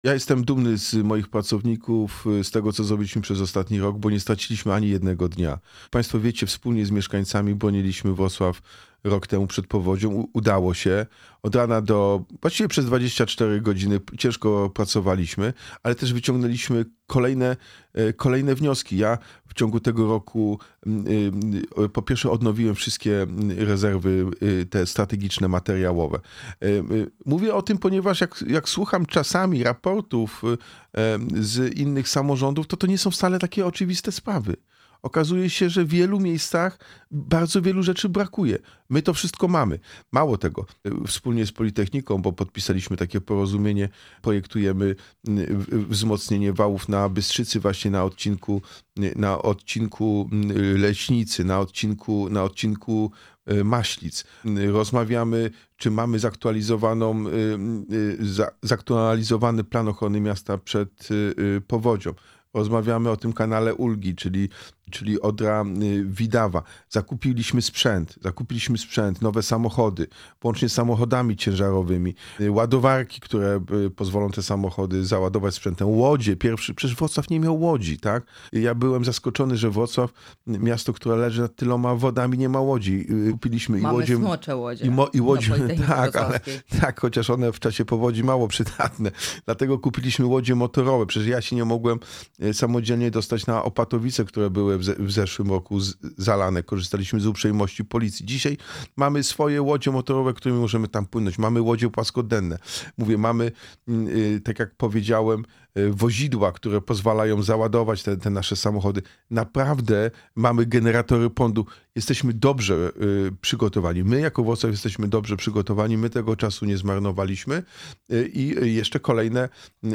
Jacek Sutryk – prezydent Wrocławia był naszym gościem.